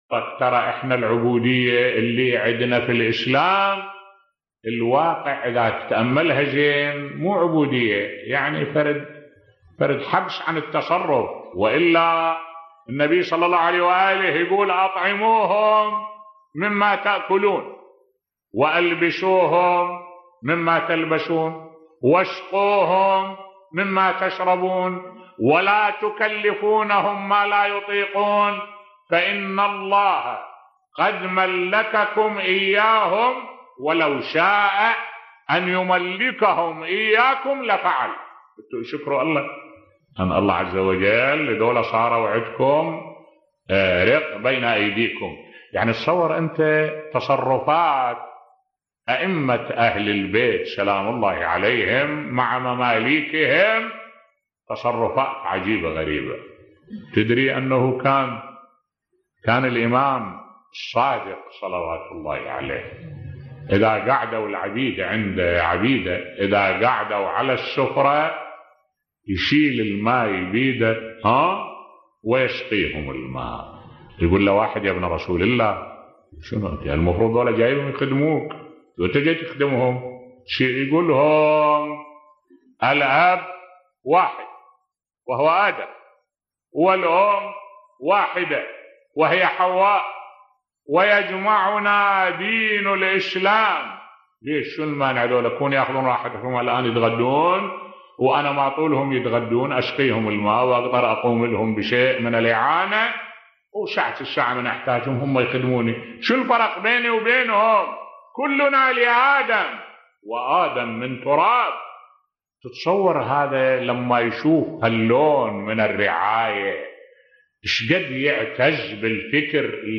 ملف صوتی كيف كان يتعامل المعصومين (ع) مع الخدم بصوت الشيخ الدكتور أحمد الوائلي